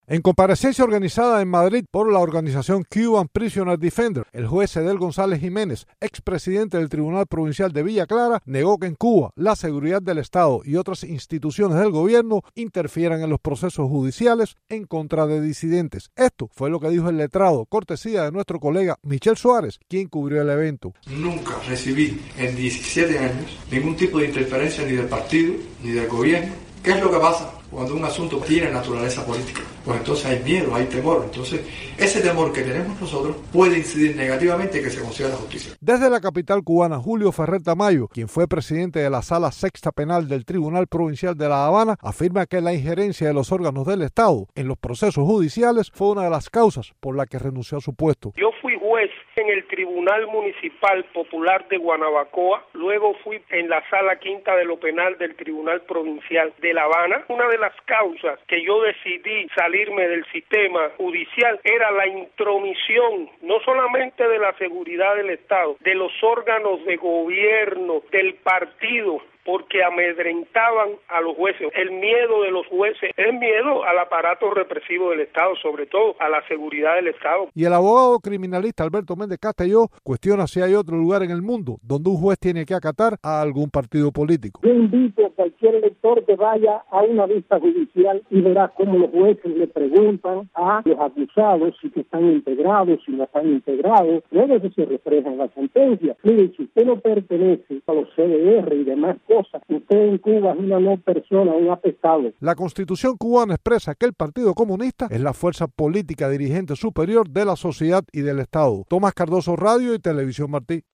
La intromisión de la Seguridad del Estado, el Partido Comunista y el gobierno en las decisiones de los jueces cubanos es un hecho, y “no es serio” afirmar que sus veredictos a favor del régimen se deban solamente al temor a las represalias, declaró el martes desde La Habana un jurista entrevistado por Radio Martí.